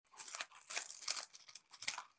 【環境音シリーズ】自動販売機
今回は、どこにでもある自動販売機で収録しました。
交通量の少ない時間帯に収録しました。
TASCAM(タスカム) DR-07Xのステレオオーディオレコーダー使用しています。